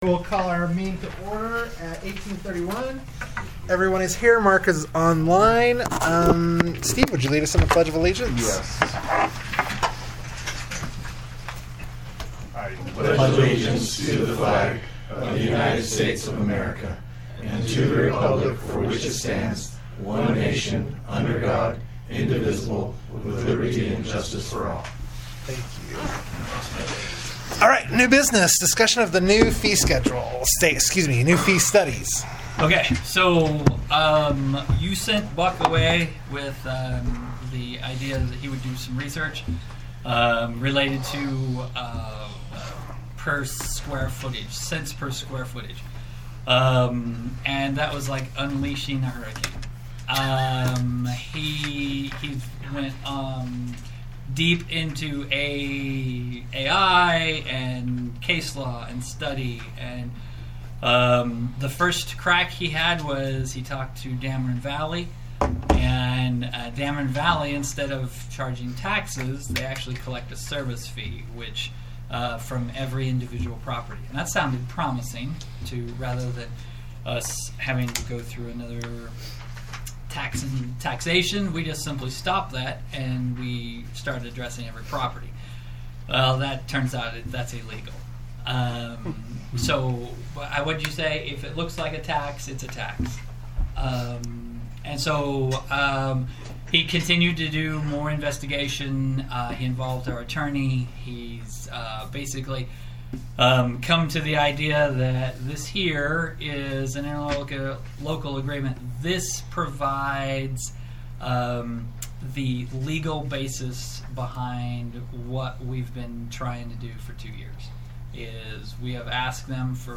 Board Meeting
Notice is hereby given that the North Tooele Fire Protection Service District will hold a board meeting on December 2, 2025, at 6:30 p.m. at the Stansbury Park Fire Station, 179 Country Club, Stansbury Park, UT.